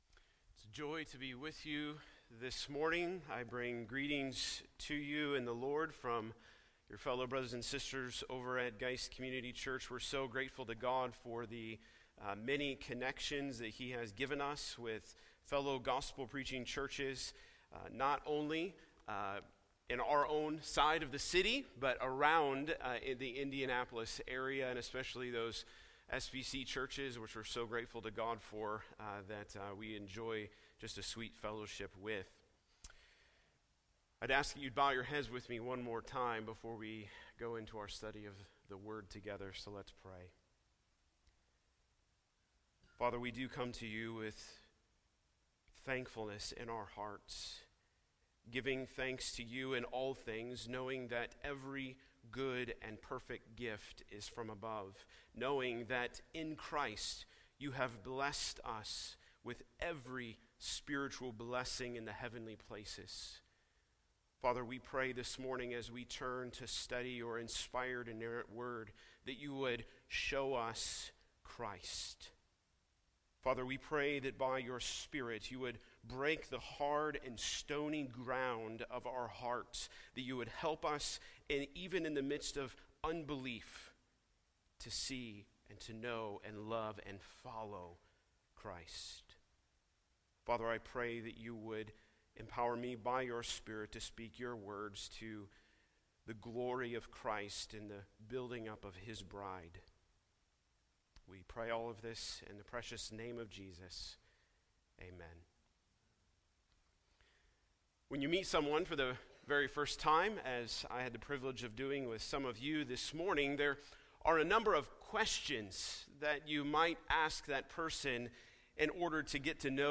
Sermon Audio 2019 November 24